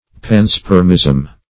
\Pan`sperm"ism\ Same as panspermia .